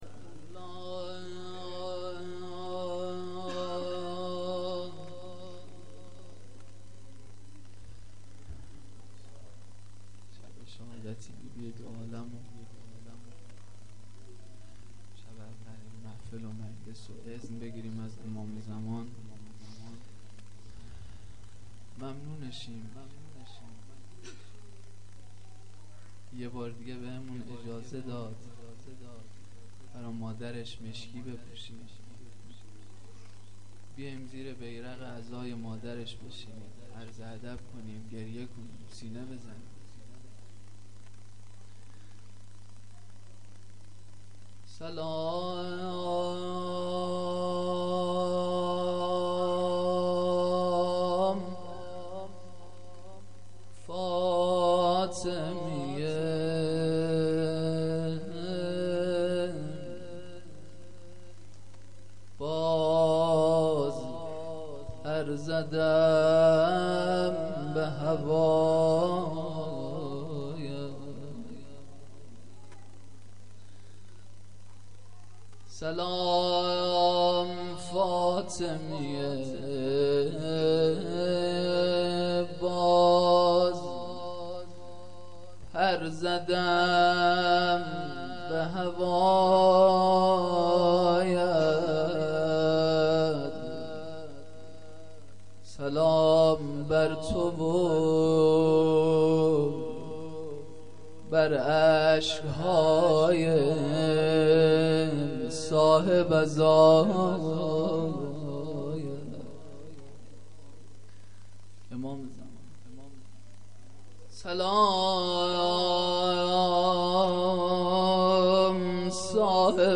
شب اول مراسم عزای مادر سادات حضرت فاطمه زهرا (س)-فاطمیه اول